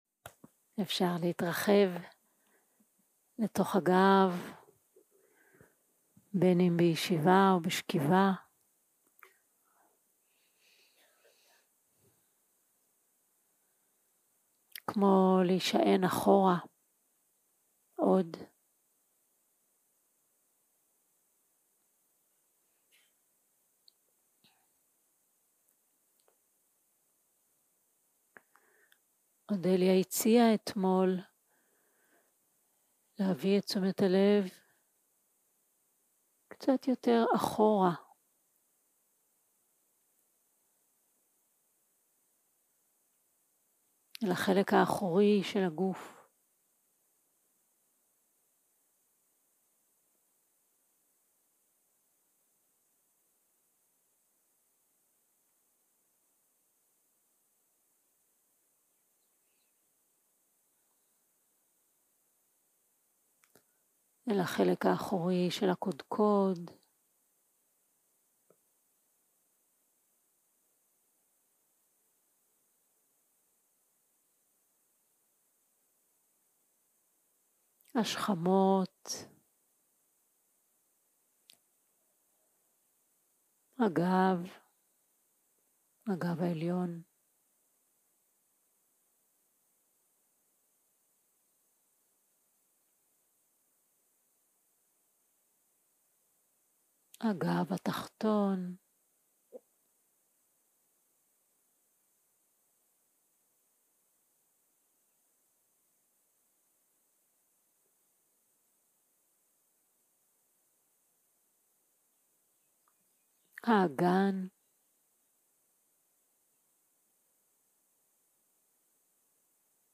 יום 3 - הקלטה 5 - בוקר - הנחיות למדיטציה
סוג ההקלטה: שיחת הנחיות למדיטציה